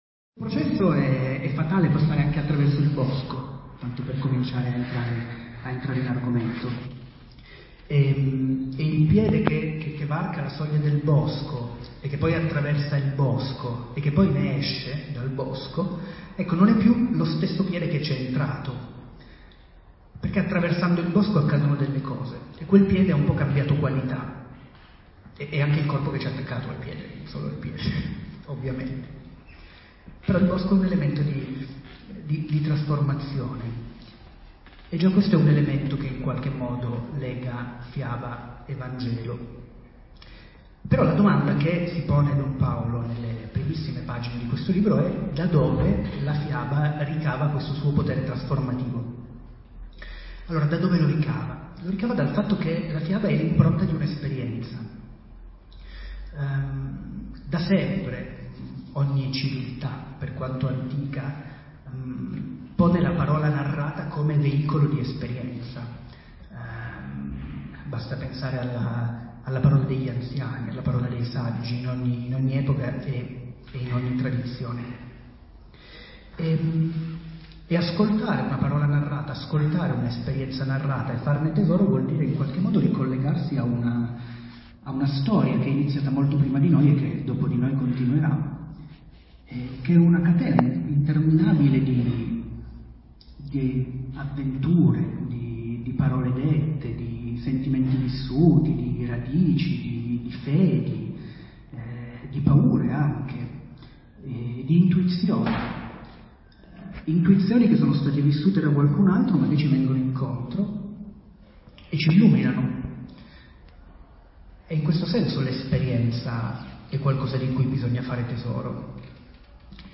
Presentazione al Circolo dei lettori di Torino.